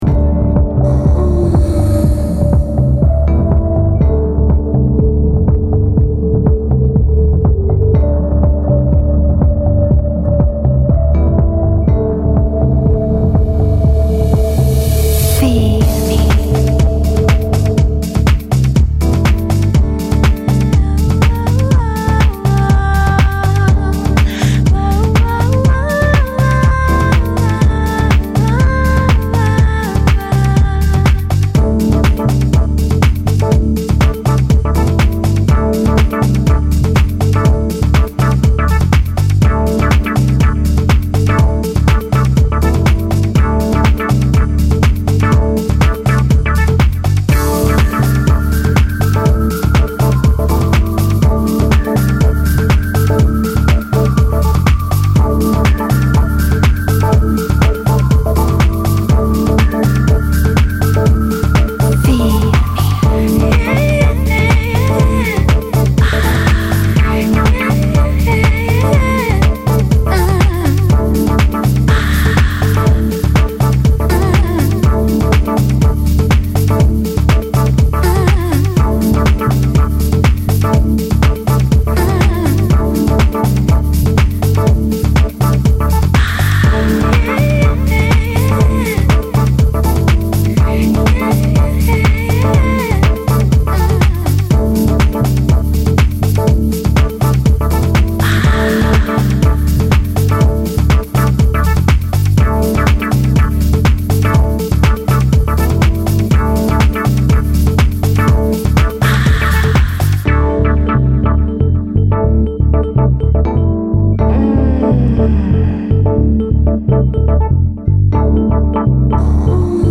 Du Groovy au Clubbing sont au rendez-vous de l’essentiel 10.